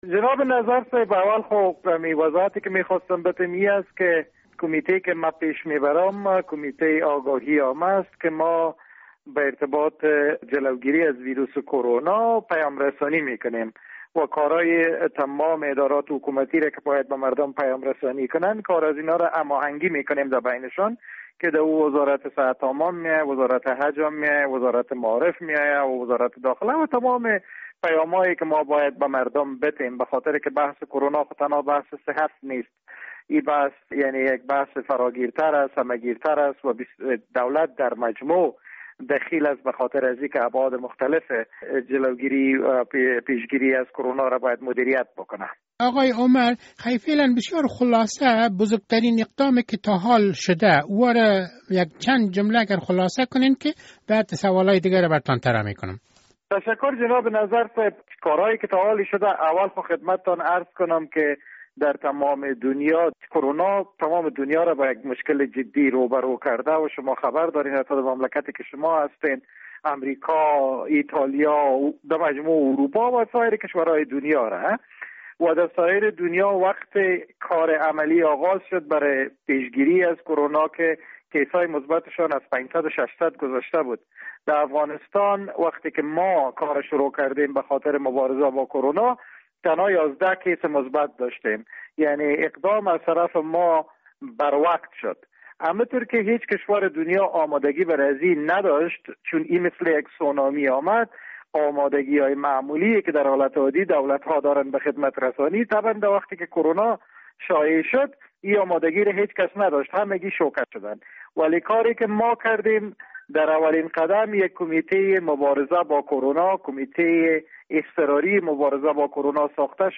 مصاحبه با وحید عمر